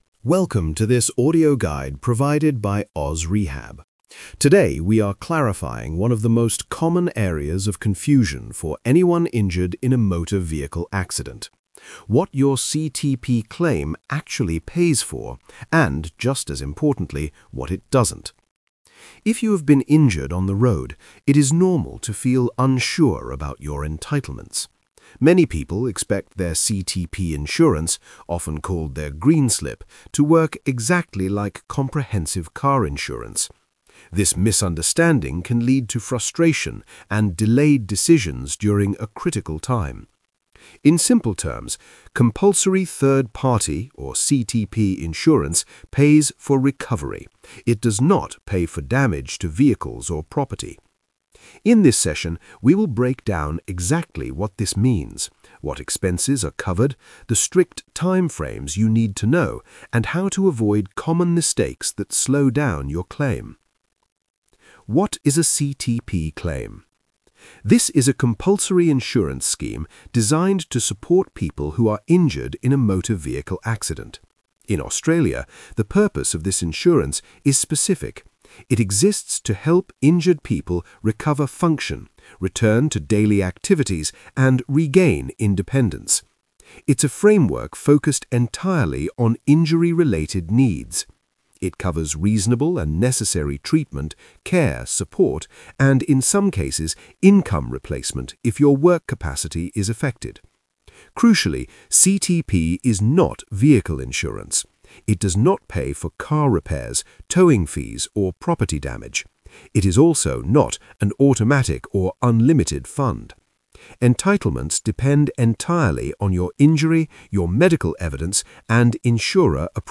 🎧 Listen to the audio version Single-host narration